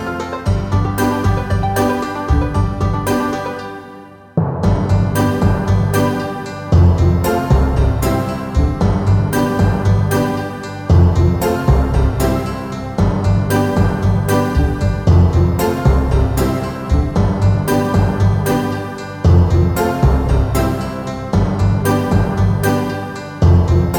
no Backing Vocals at all Easy Listening 4:28 Buy £1.50